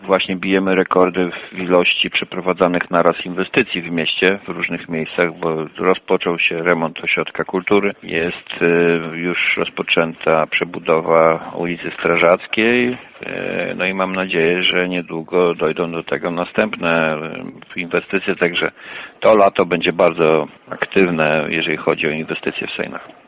Koszt remontu to ponad 2,5 miliona złotych. – To rok bogaty w inwestycje – mówi Arkadiusz Nowalski, burmistrz Sejn.